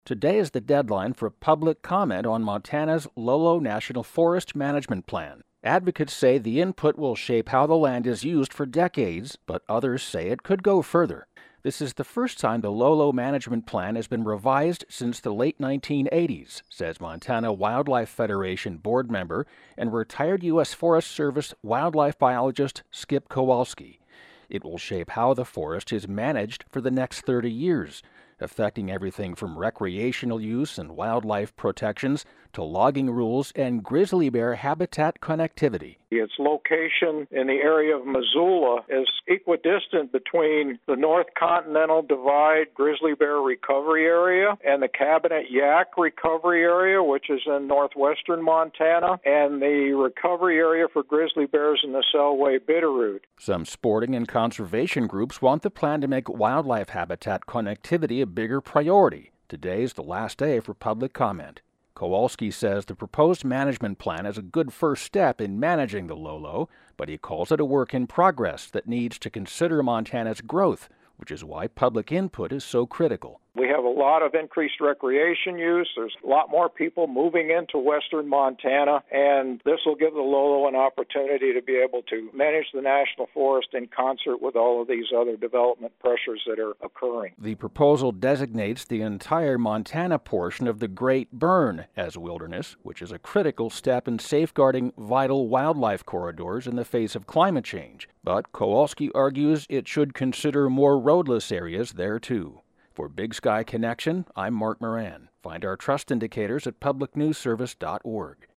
Category: City Desk